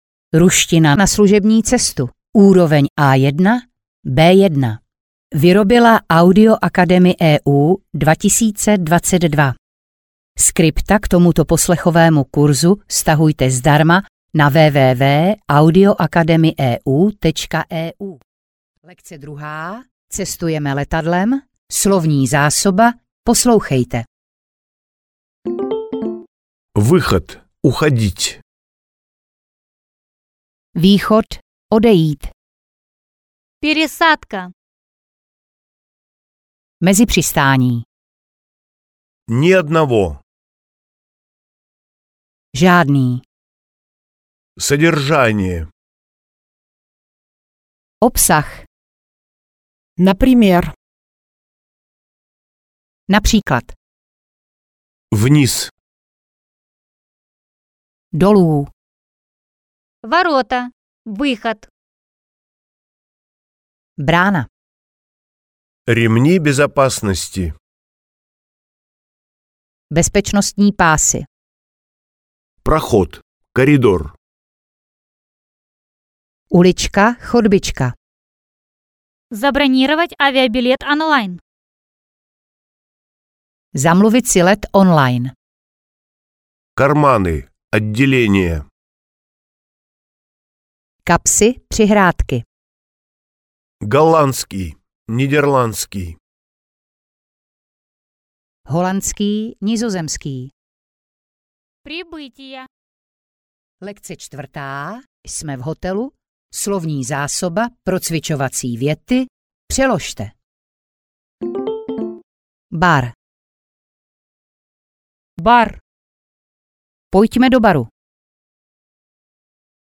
Audio knihaRuština na cesty A1-B1
Ukázka z knihy